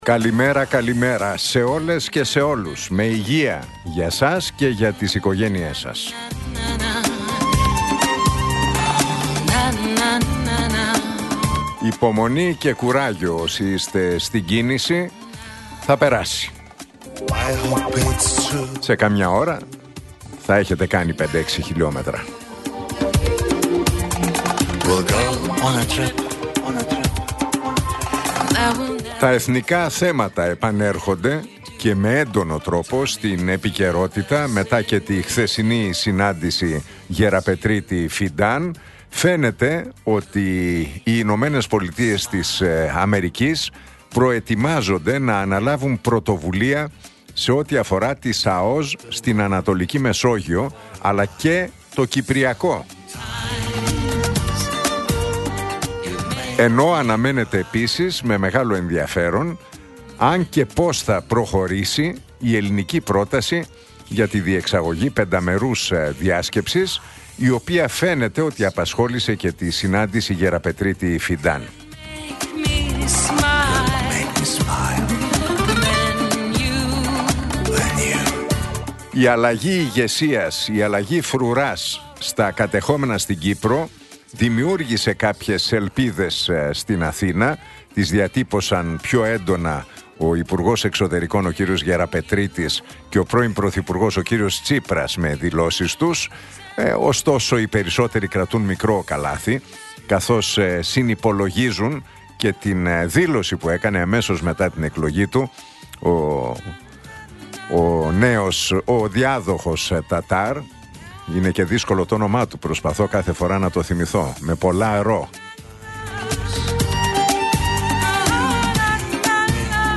Ακούστε το σχόλιο του Νίκου Χατζηνικολάου στον ραδιοφωνικό σταθμό Realfm 97,8, την Τρίτη 21 Οκτώβριου 2025.